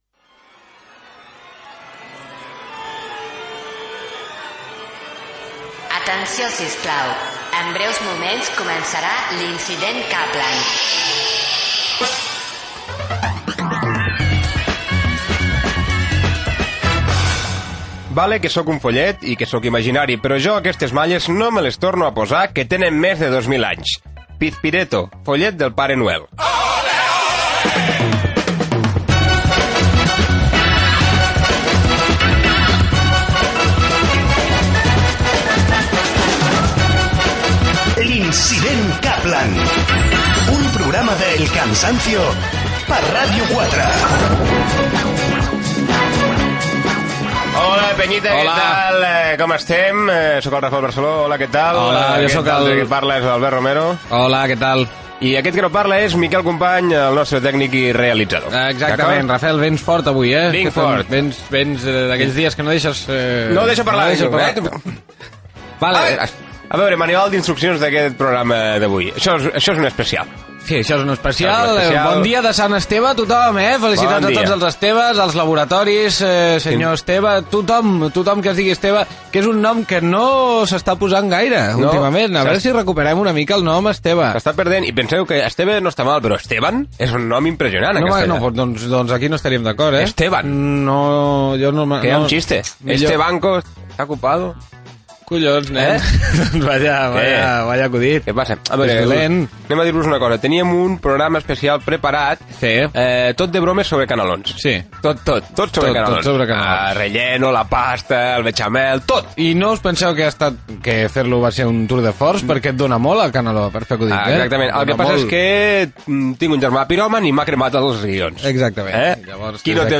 ee8eea684039f13f0f98eae70a5ec1c1fc087278.mp3 Títol Ràdio 4 Emissora Ràdio 4 Cadena RNE Titularitat Pública estatal Nom programa L'incident Kàplan Descripció Careta, equip, comentari sobre el programa del dia de Sant Esteve, indicatiu, la paraula "enfitat", la radionovel·la "La família Cortisona", els regals del Pare Noel, les calces i els tangues, els canalons... Gènere radiofònic Entreteniment